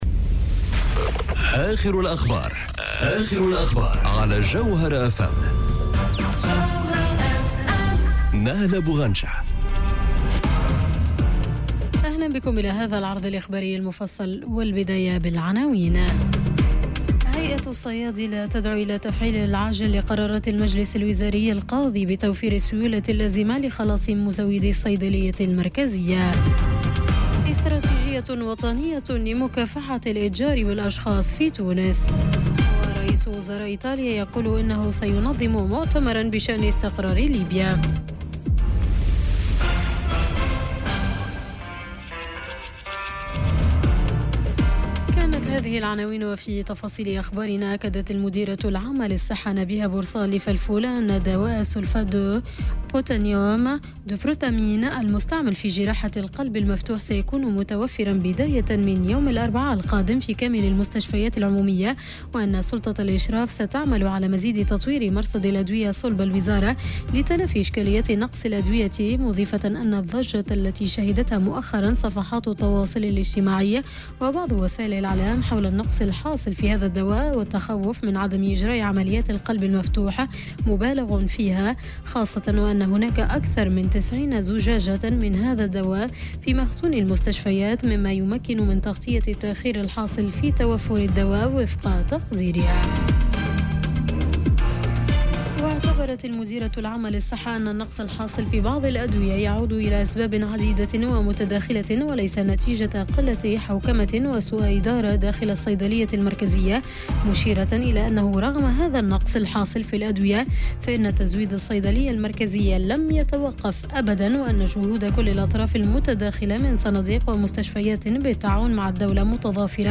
نشرة أخبار منتصف الليل ليوم الثلاثاء 31 جويلية 2018